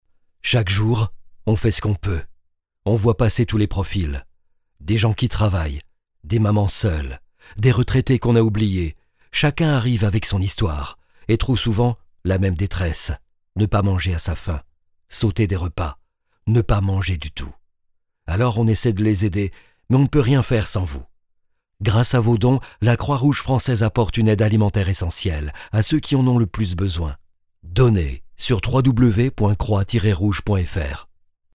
Voix off
Je me déplace en studio ou j'enregitre à distance depuis mon studio pro équipé de ProTools - micro Neumann cabine accoustique.
31 - 55 ans - Baryton-basse